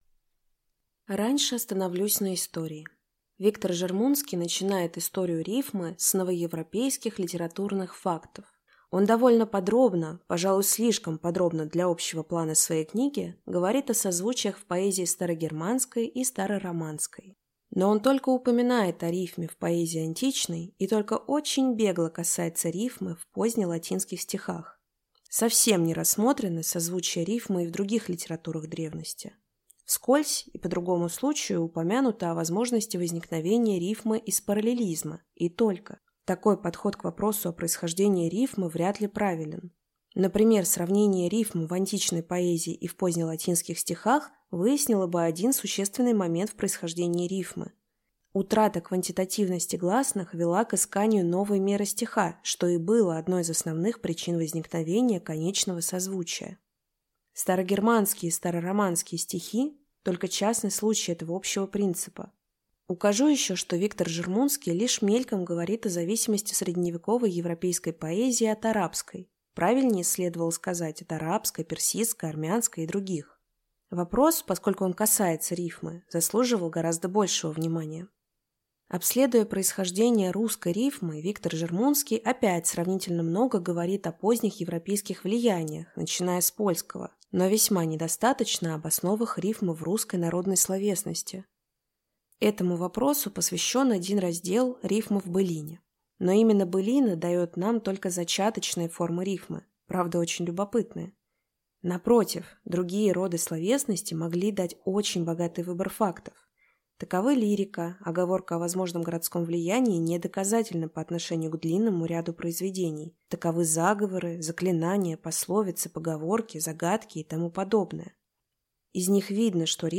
Аудиокнига О рифме | Библиотека аудиокниг